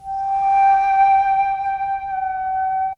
ZG FLUTE 8.wav